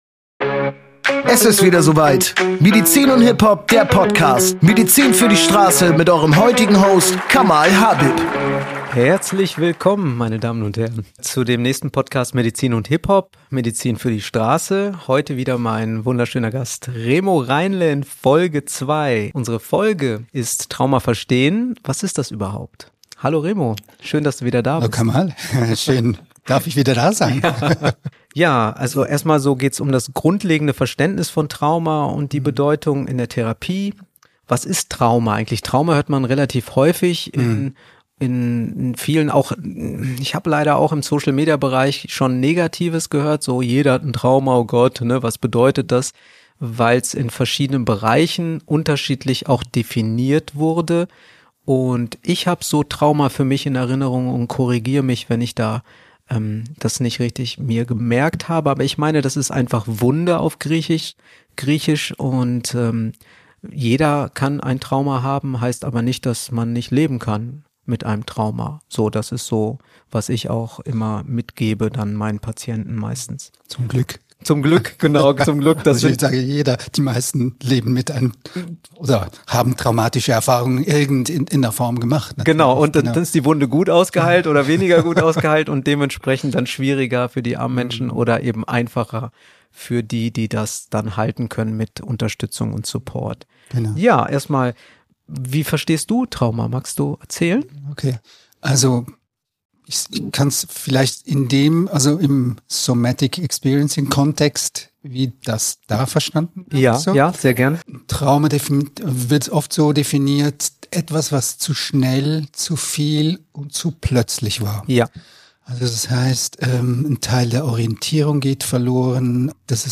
Eine Grundlagenfolge – ruhig, erklärend, ohne Druck.